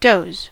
doze: Wikimedia Commons US English Pronunciations
En-us-doze.WAV